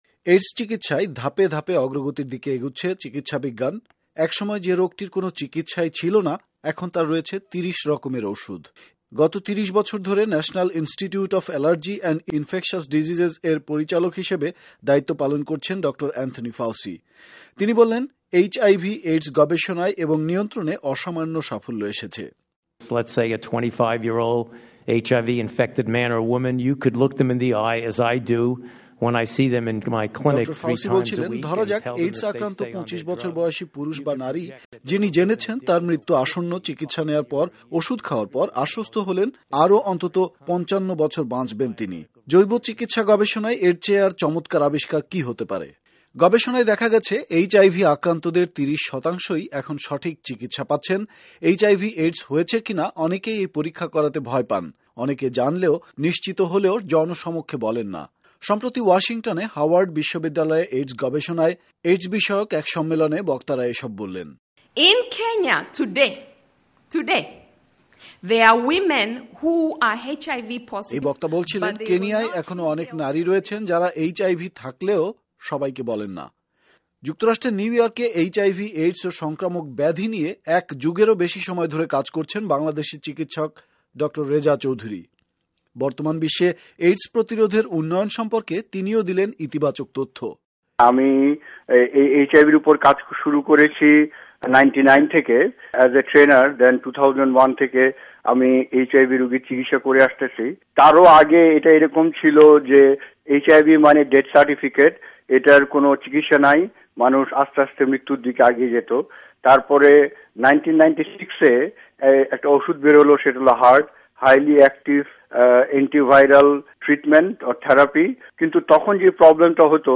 বিশেষ প্রতিবেদন
সাক্ষাত্কার